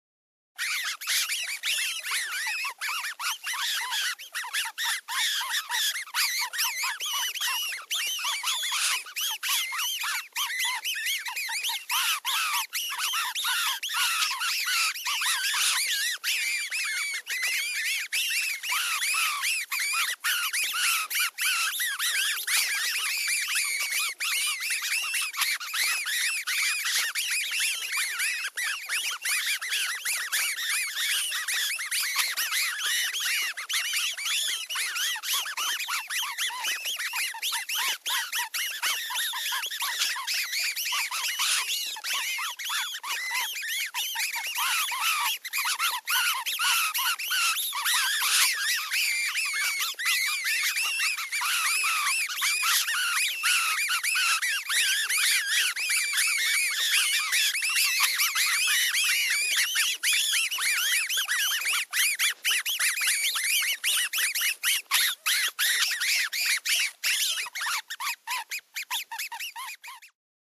ANIMALS WILD: Rats squeaking.